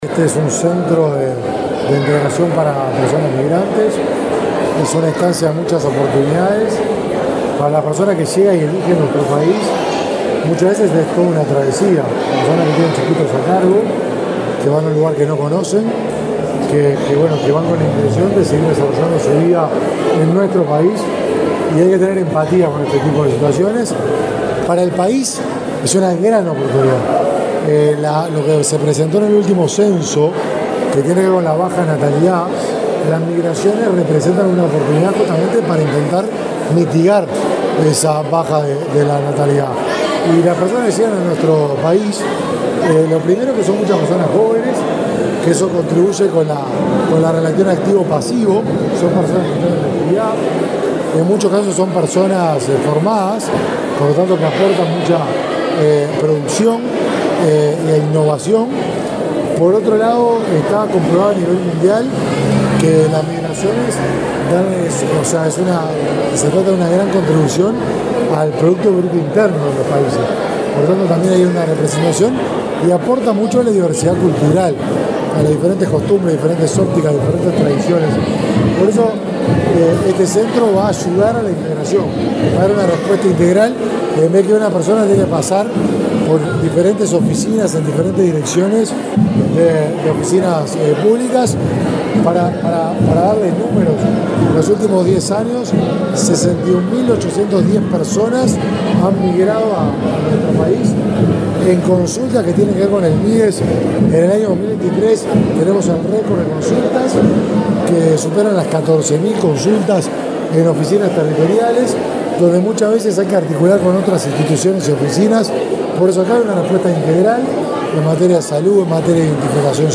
Declaraciones a la prensa del ministro de Desarrollo Social, Martín Lema
Declaraciones a la prensa del ministro de Desarrollo Social, Martín Lema 18/12/2023 Compartir Facebook X Copiar enlace WhatsApp LinkedIn El ministro de Desarrollo Social, Martín Lema, y su par de Vivienda, Raúl Lozano, participaron en el acto de recepción de la obra del Centro de Encuentro para Migrantes, en Montevideo. Luego Lema dialogó con la prensa.